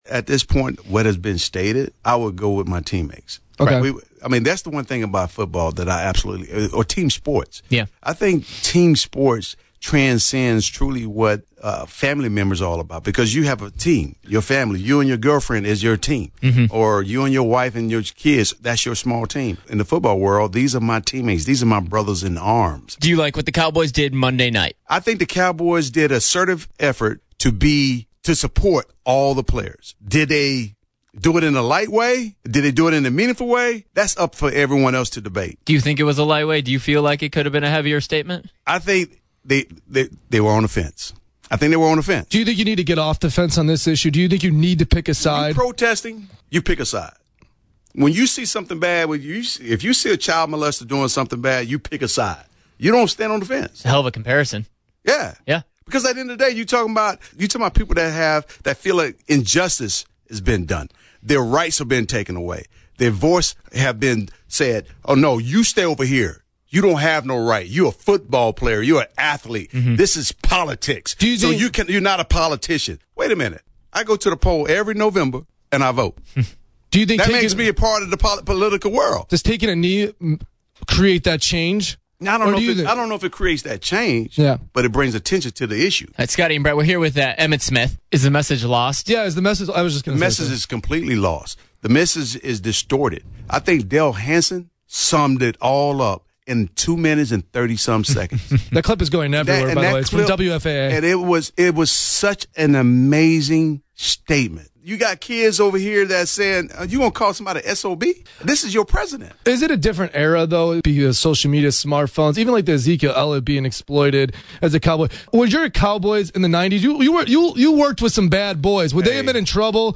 In an interview on HOT 93.3, Smith says if you’re protesting, you pick a side, and the Cowboys didn’t do that.
raw-emmitt-smith-on-hot-933.mp3